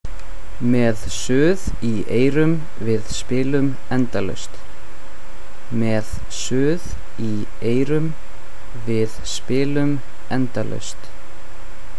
How to pronounce Sigur Rós’ new album name:
SR - album pronounce.mp3